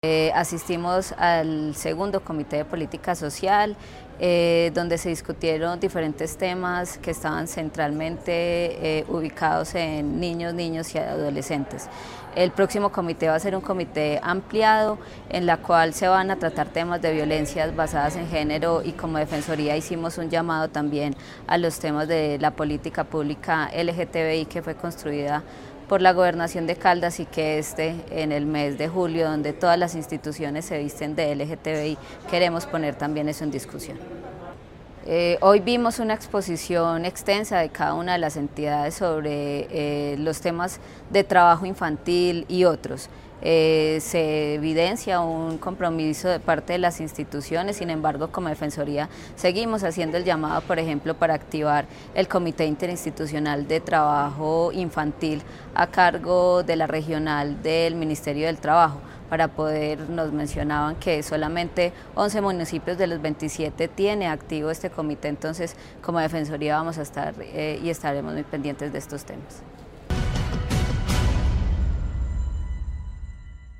Chato Gómez, defensor del pueblo regional.